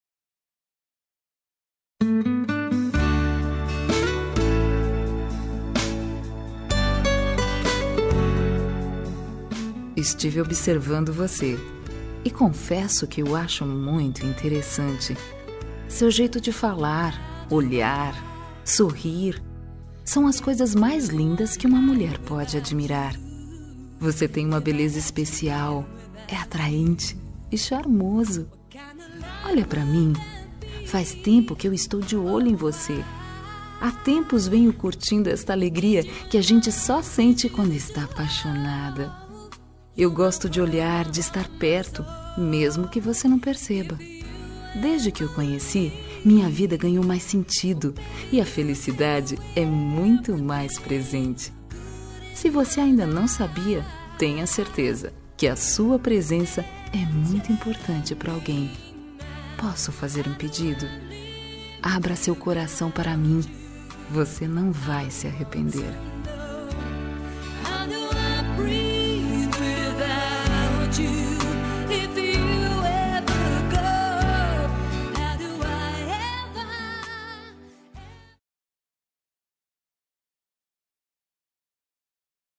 Telemensagem de Paquera – Voz Feminina – Cód: 2152 – Linda
Paquera Fem 2152 estou de olho.mp3